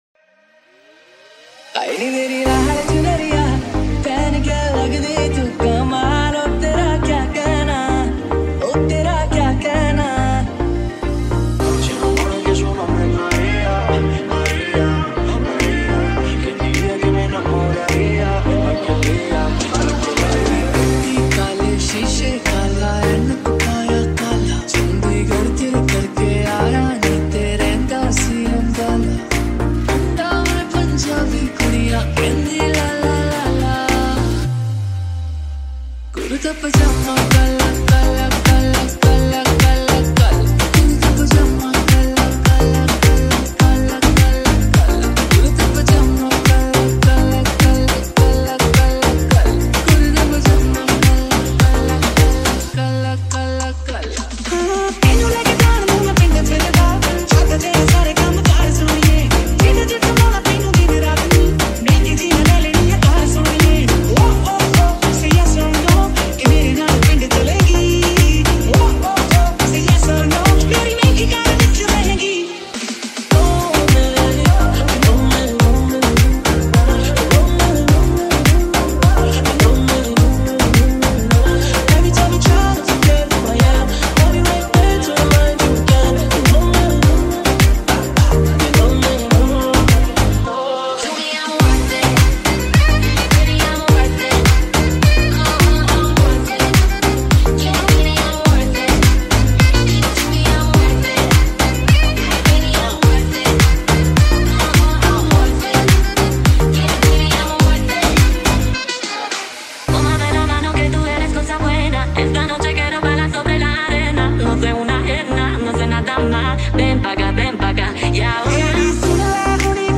Dance Songs | Club Hits